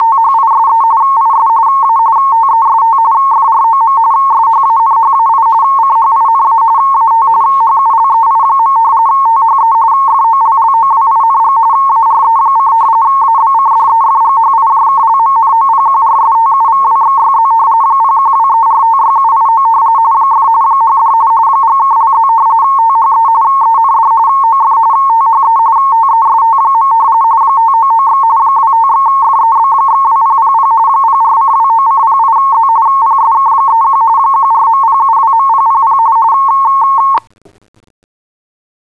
RTTY TEST.wav